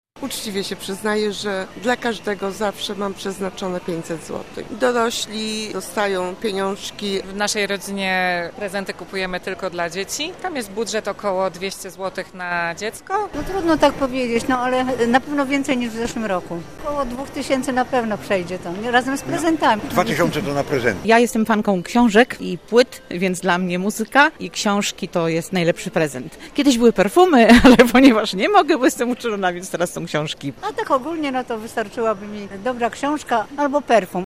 zapytał mieszkańców Elbląga, ile wydadzą i co chcieliby znaleźć pod choinką.